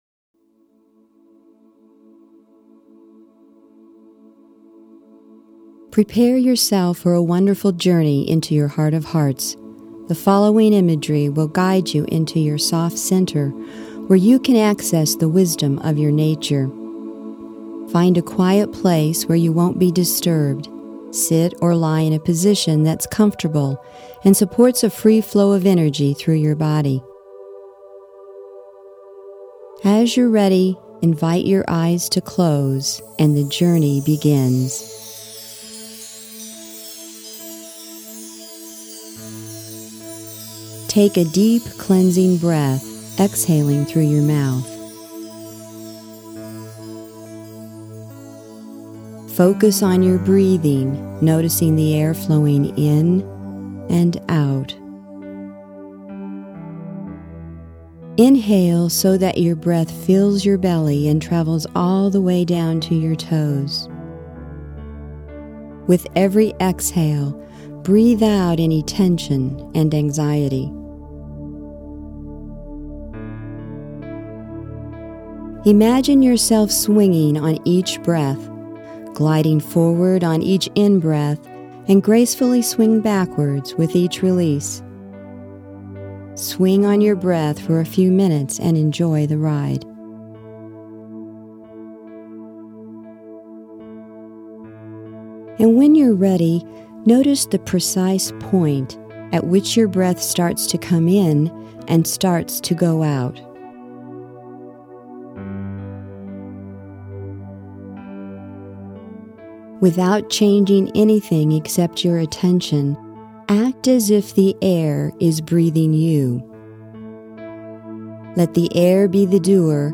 free meditation... Take a few minutes to stop what you are doing... close your door... put your feet up... and listen to the meditation. Notice how quickly, your body remembers it’s natural state of relaxation and how refreshing it feels.